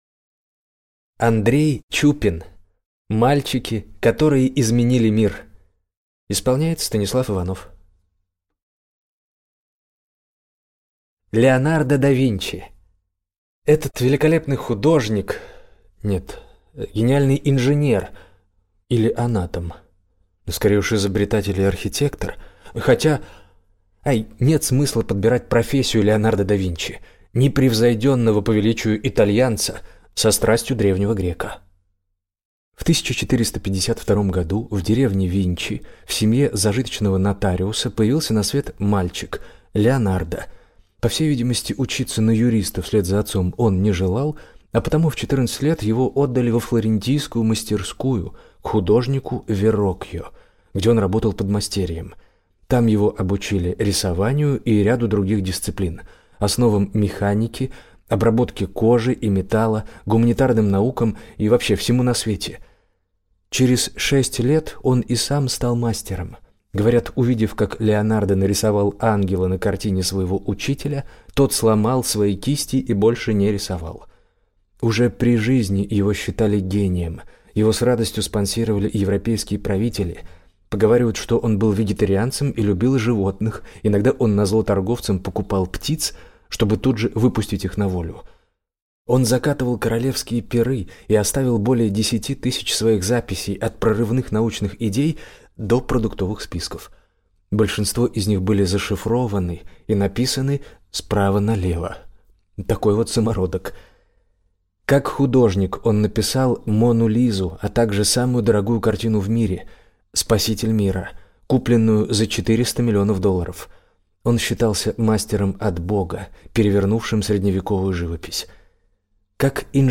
Аудиокнига Мальчики, которые изменили мир | Библиотека аудиокниг